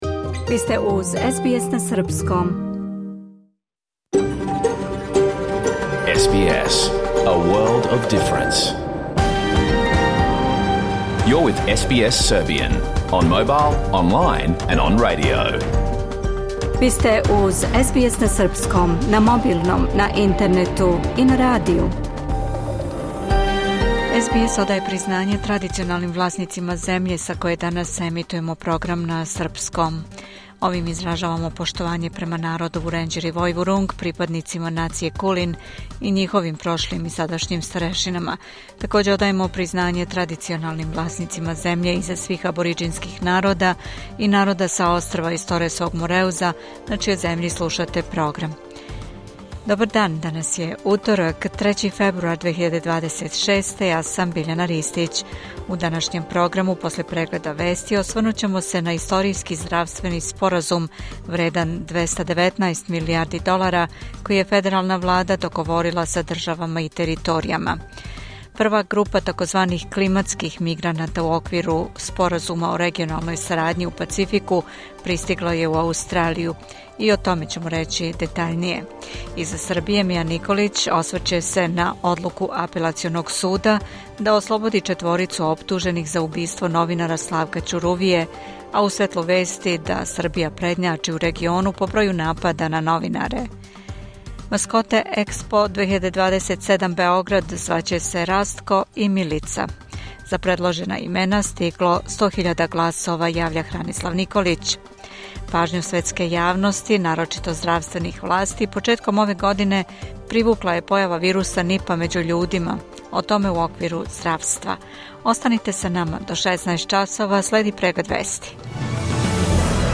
Програм емитован уживо 3. фебруара 2026. године